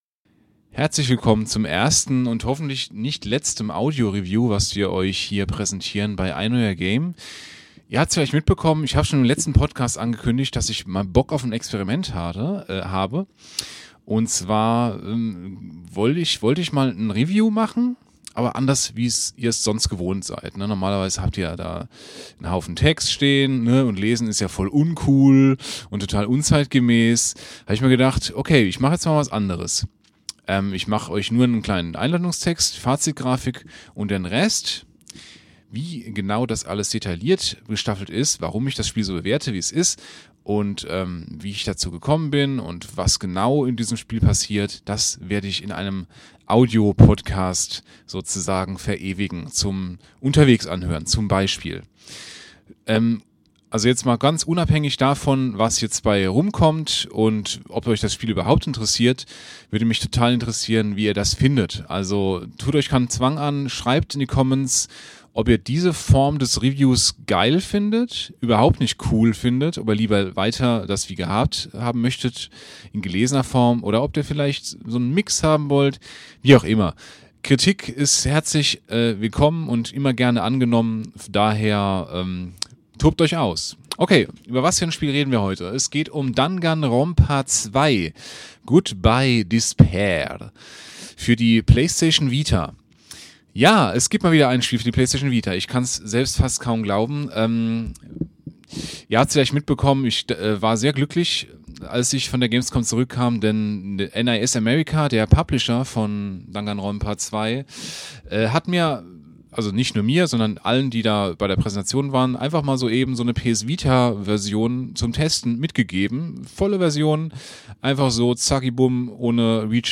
Ob die Mischung aus Zero Escape und Ace Attorney auch außerhalb der Hope´s Peak Academy funktioniert, hört ihr in meinem Audio-Review!
Bei diesem Review tobe ich mich mal in einem kleinen Experiment aus und serviere euch keinen ellenlangen Text, sondern quasi einen Audio-Podcast zum Spiel.